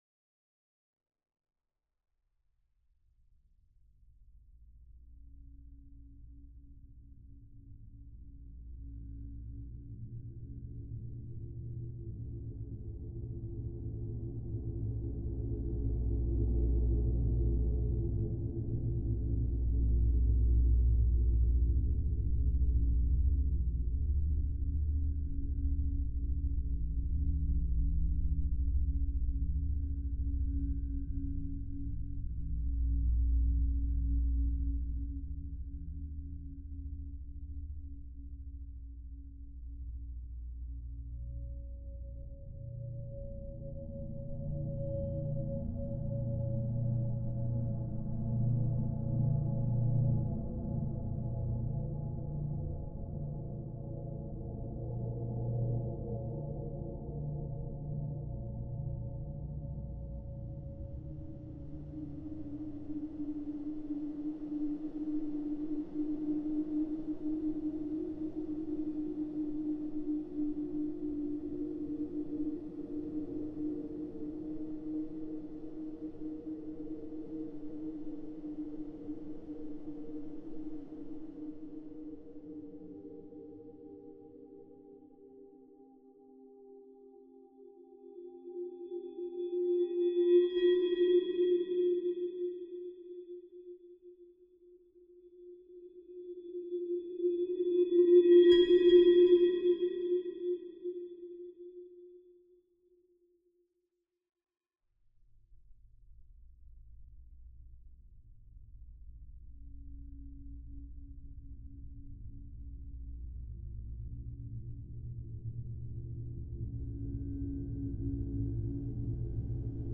bande sonore accompagnant le solo:
Pour trompette et électronique